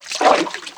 STEPS Water, Walk 05.wav